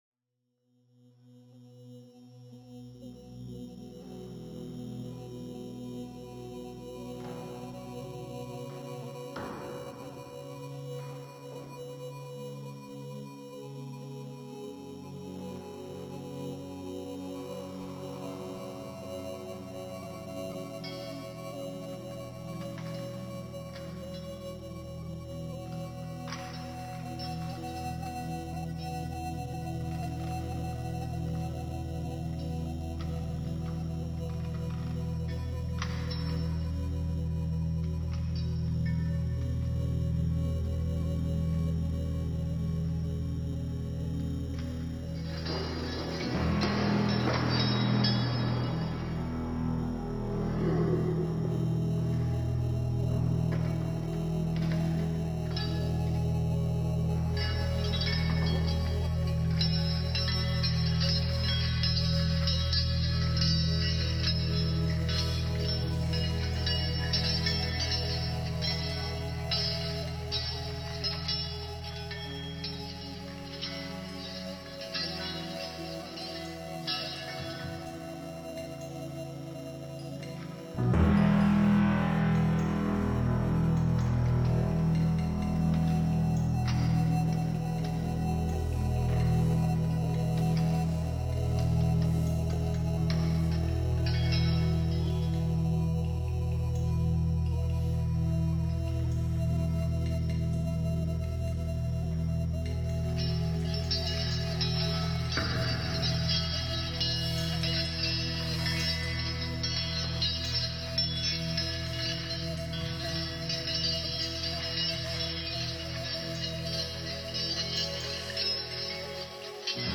• Guts salvaged from a Canal Street surplus store windup toy
• Modified and mounted in window frame
iPad: AUM (host), B00GA, DroneLab
Guitar: Fender Strat → EHX Pico POG → Quilter amp
Empress Zoia: Pride & Prevarication patch